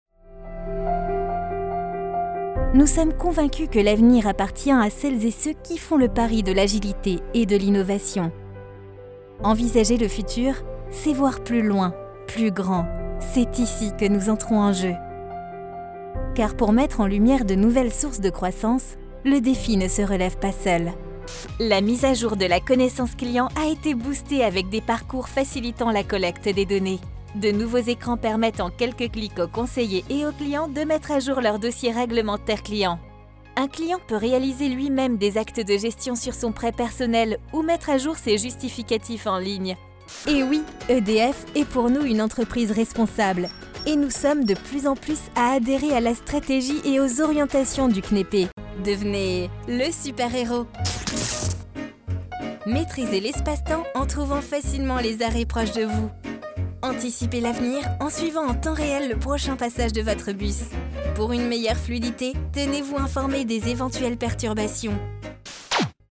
Vos projets sur mesure réalisés par une comédienne voix off professionnelle
Un studio d’enregistrement professionnel pour une voix sur mesure :
MEDLEY Institutionnel / Corporate
Voix impliquée, souriante, jeune..